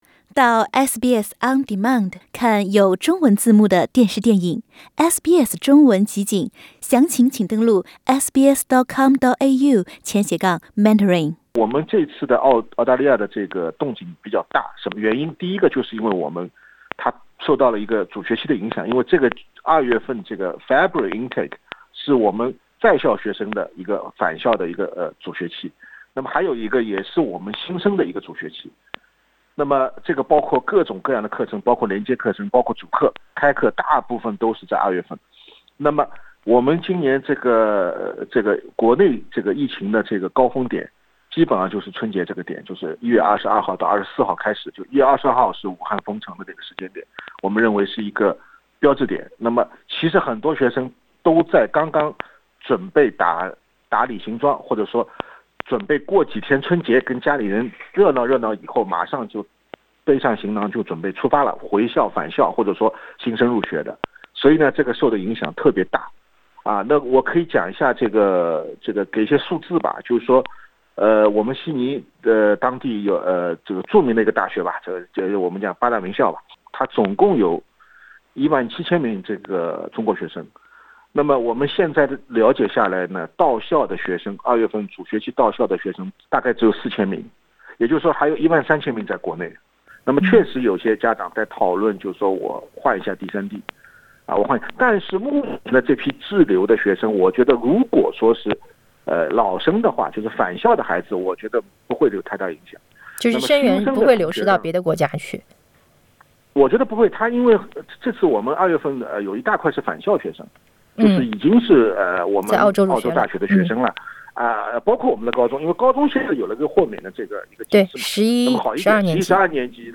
点击最上方图片收听采访；嘉宾观点，不代表本台立场。